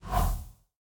sounds / mob / breeze / land1.ogg